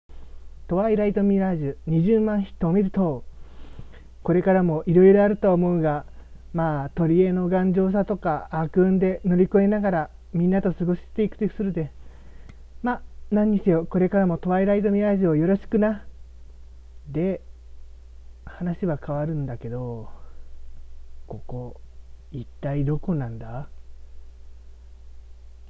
とわみら20万ヒット記念、お祝いボイス